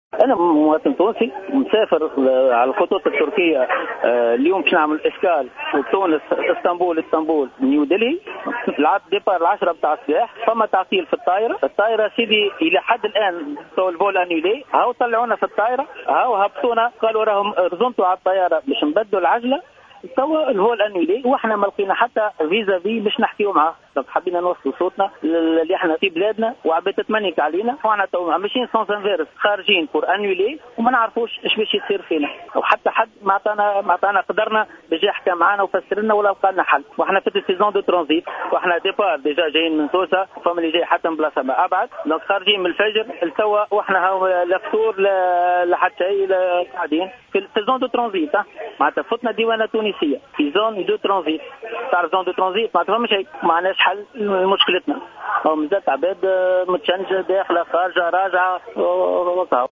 أحد المسافرين